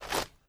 STEPS Dirt, Walk 07.wav